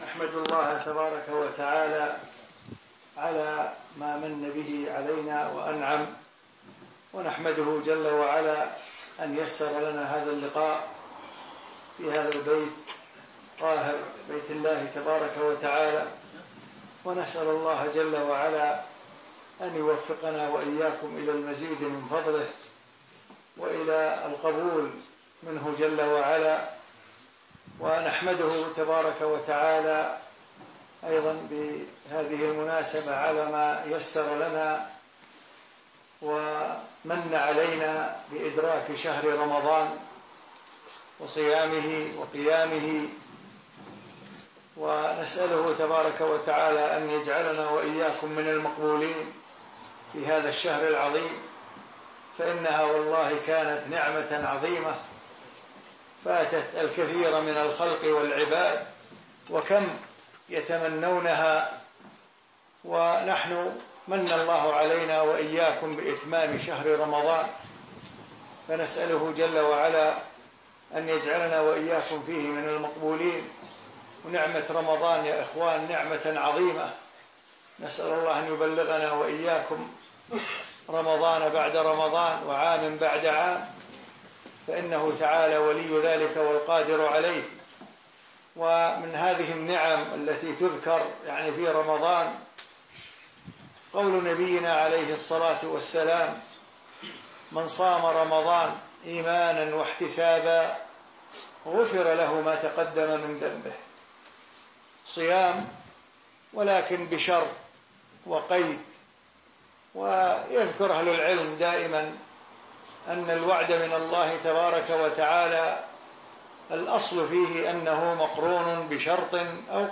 فضل صيام رمضان وقيامة محاضرة في مدينة برمنجهام البريطانية